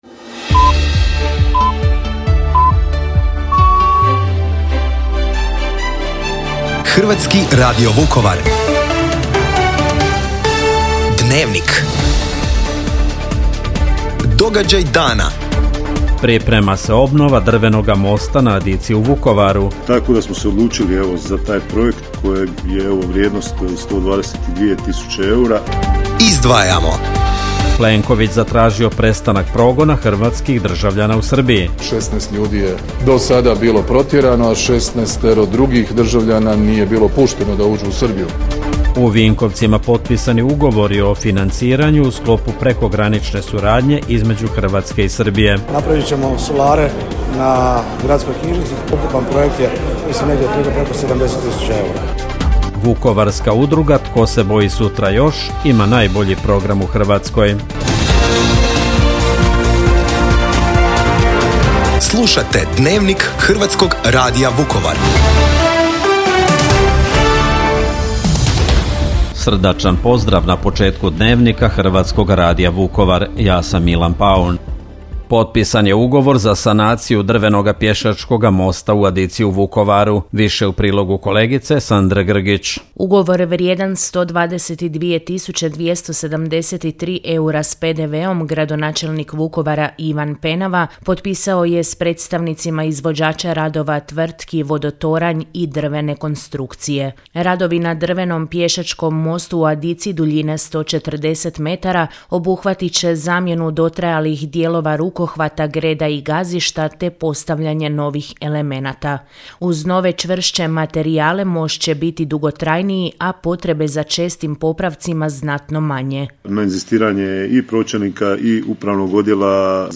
Dnevnik, utorak, 15. travnja 2025.